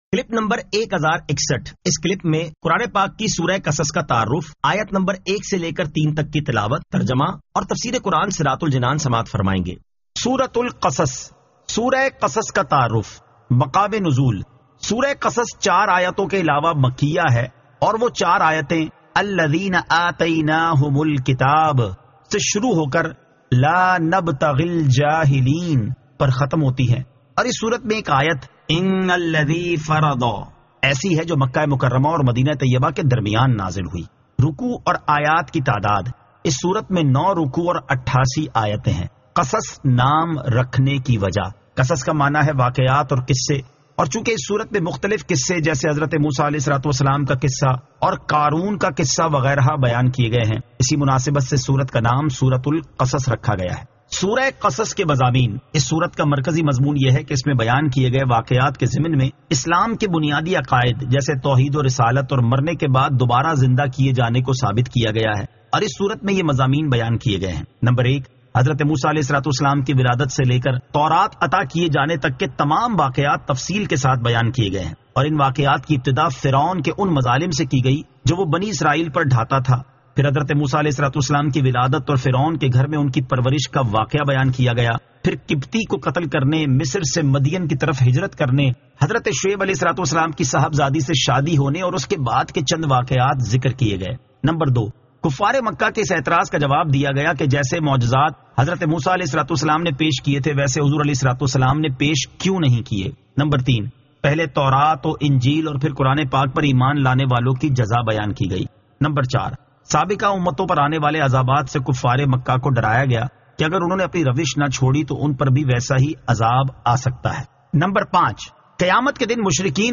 Surah Al-Qasas 01 To 03 Tilawat , Tarjama , Tafseer